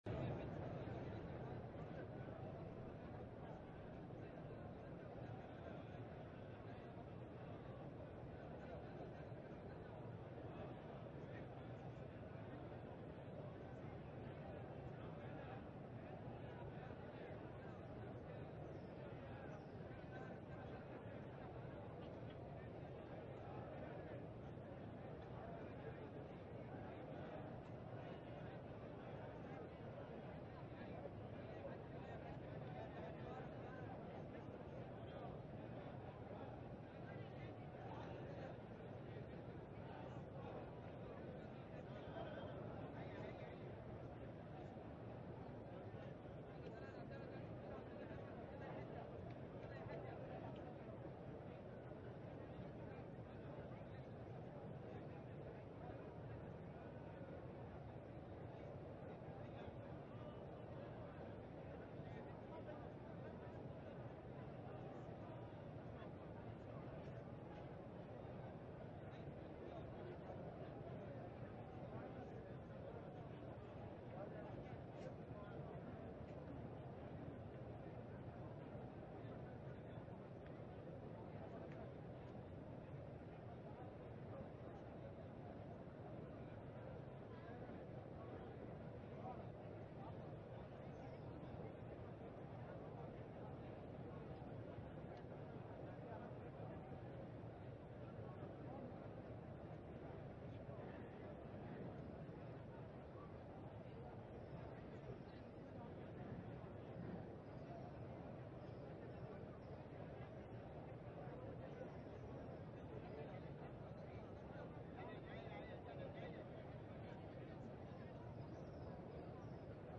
تهجد اليلة السادسة والعشرين من رمضان ١٤٣٩ من سورة المائدة اية ٤١ إلى ٨١ اية > تراويح ١٤٣٩ هـ > التراويح - تلاوات بندر بليلة